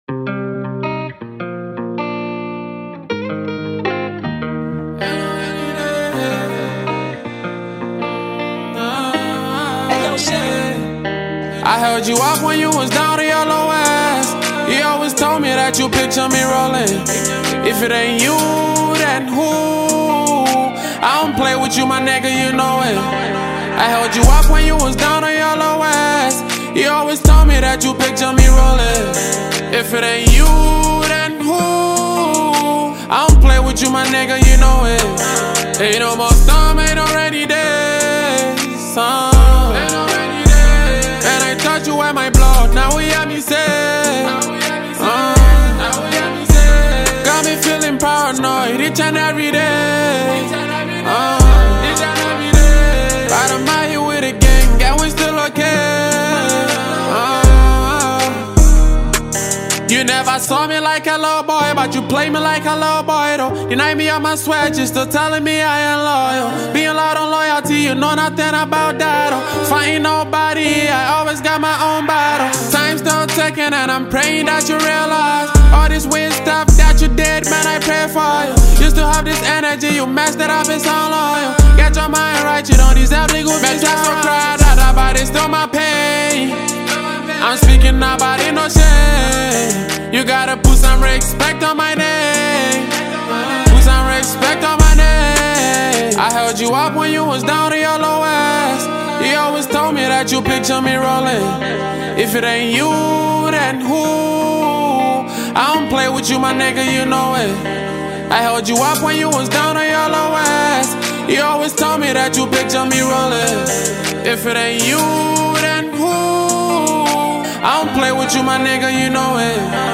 Promising and talented Ghanaian singer
blends modern beats with melodic undertones